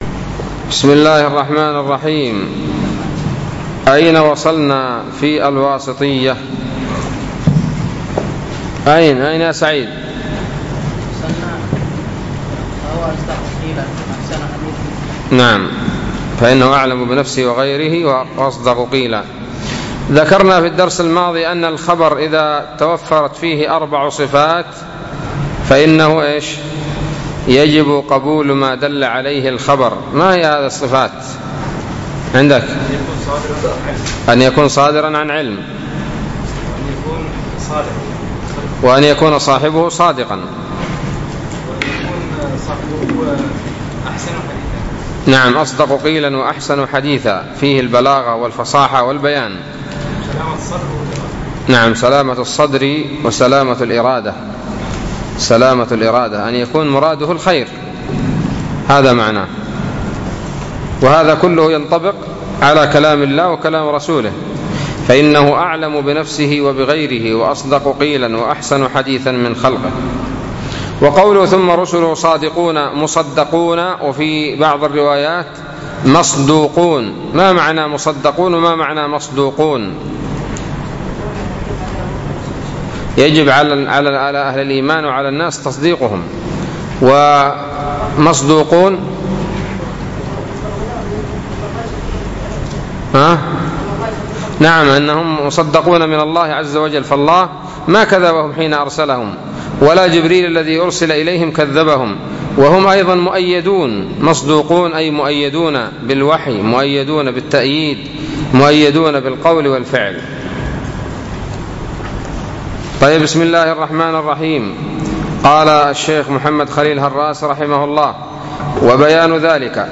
الدرس السابع والعشرون من شرح العقيدة الواسطية